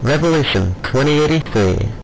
haze and ivy voice lines